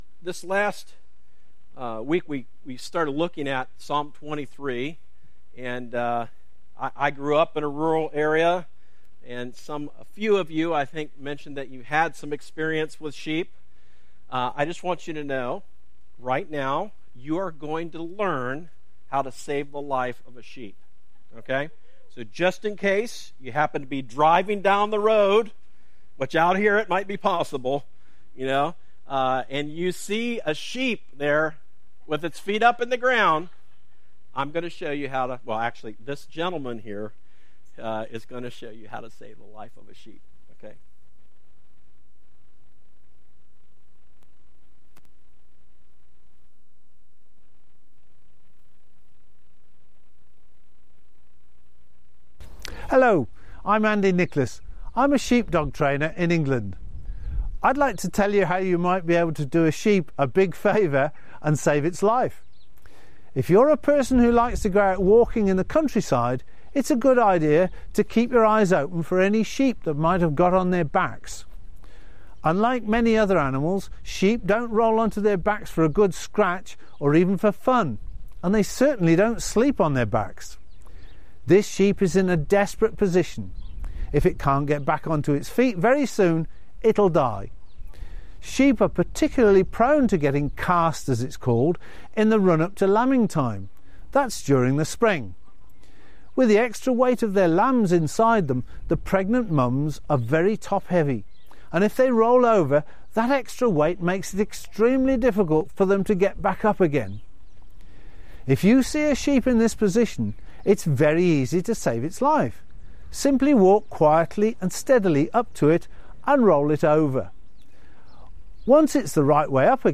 A message from the series "Living In Babylon."